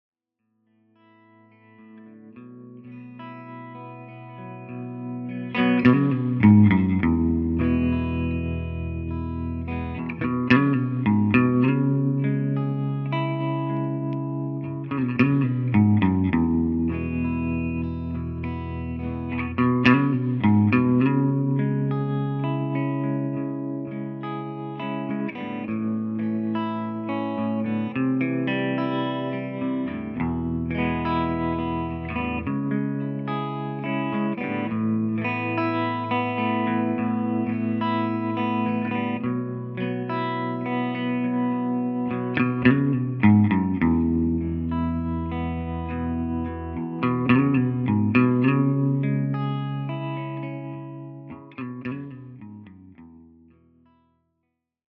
My latest guitar amp project is a stereo amp with vibrato and reverb.
vibratoslow_r1_session.flac